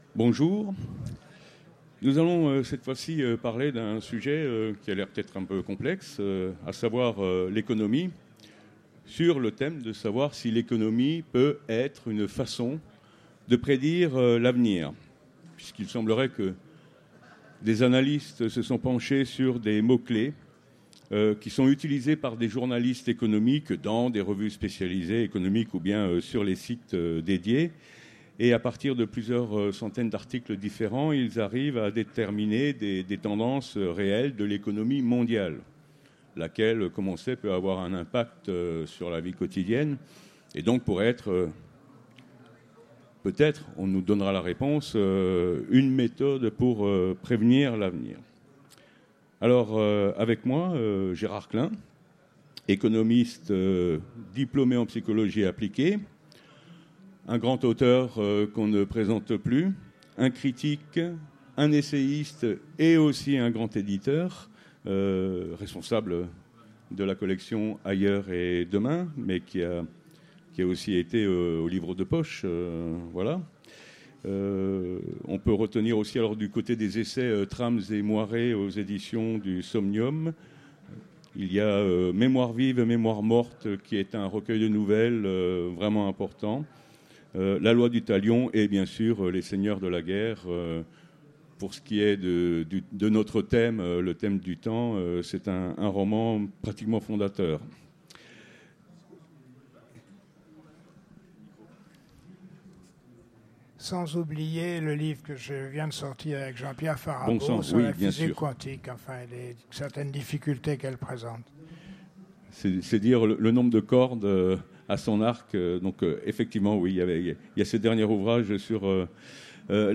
Utopiales 2017 : Conférence L’économie, une façon de prédire l’avenir ?